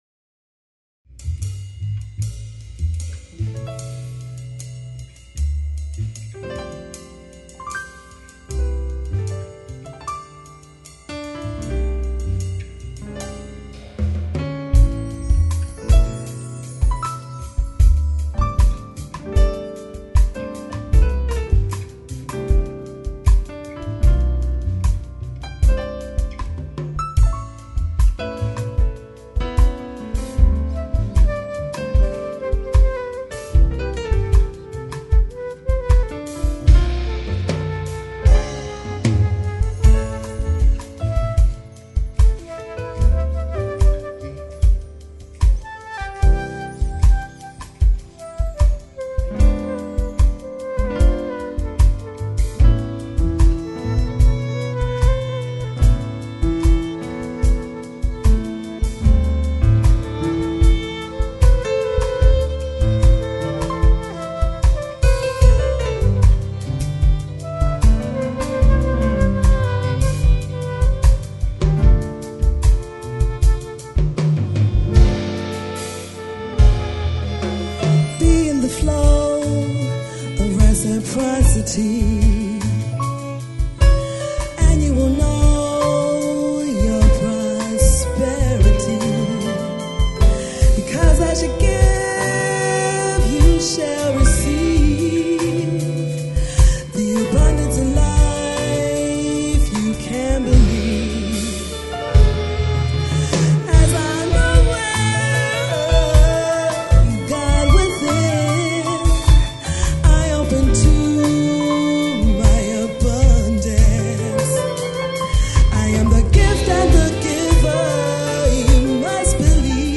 Live :: Location :: Mobile :: Recording
There was a large cast of musicians - drums, percussion, guitar, bass, 2 keys, flute, violin, five vocals - it was quite a challenge to record (I also set up and ran sound for the event).
I was also able to match the multitrack recording of the show to video from a camera in the audience.